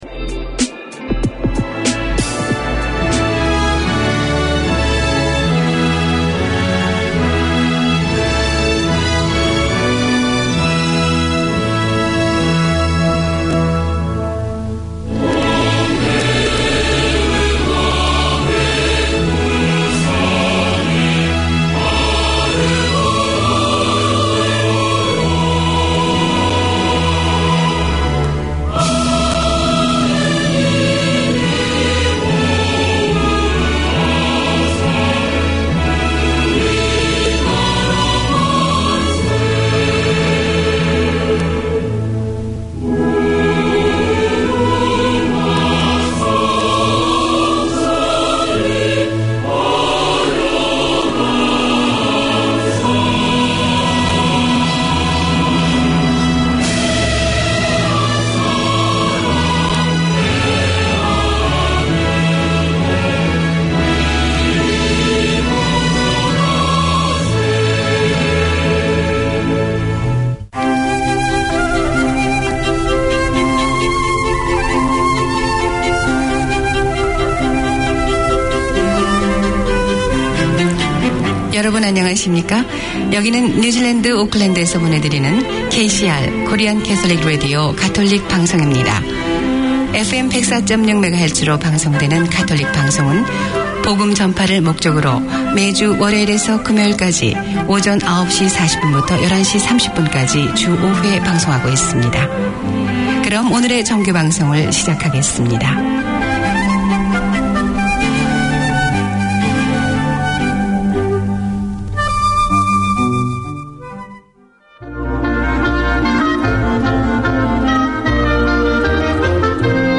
Community magazine